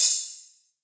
VEC3 Ride
VEC3 Cymbals Ride 01.wav